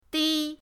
di1.mp3